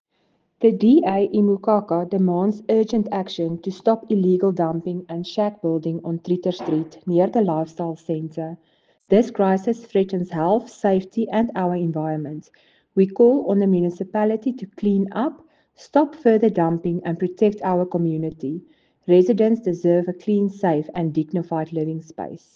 Afrikaans soundbites by Cllr Marelize Boeije and